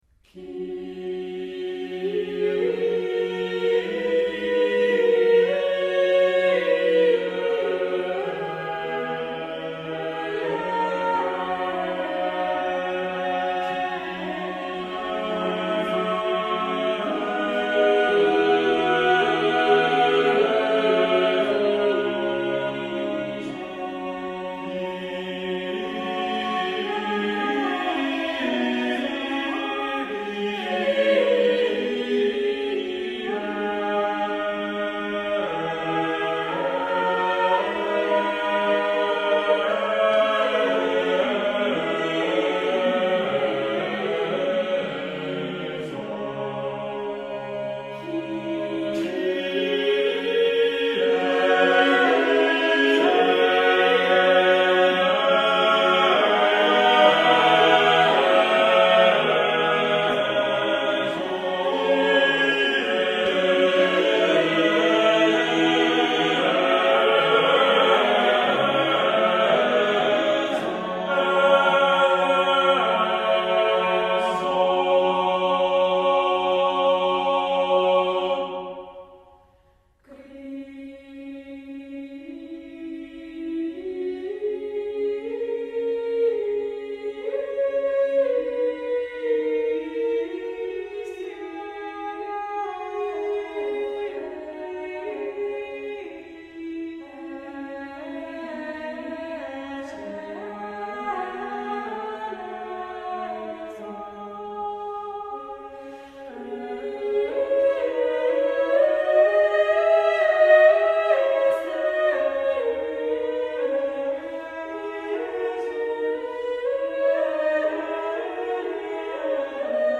Cantus firmus mass
Cantus firmus mass ~1470 (Renaissance) Group: Cyclic mass Cantus firmus - a pre-existing melody (usually in tenor) forming the basis of a polyphonic composition.